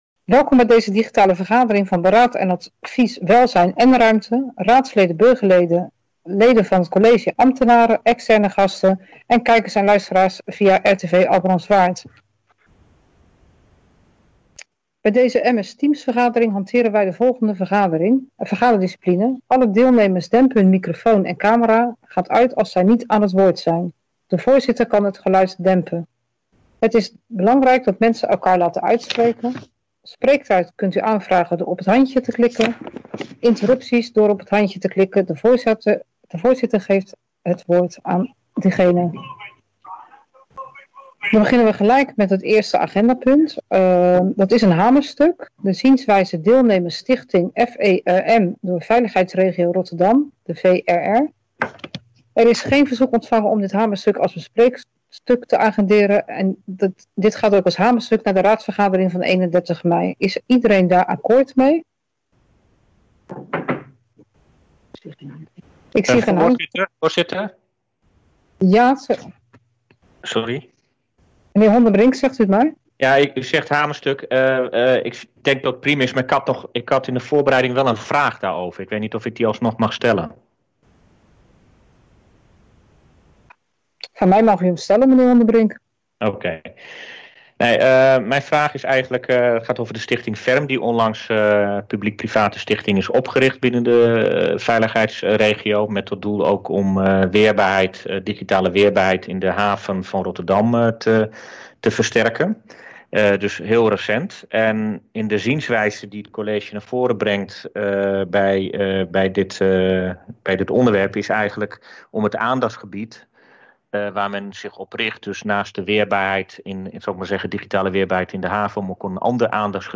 Download de volledige audio van deze vergadering
Locatie: MS Teams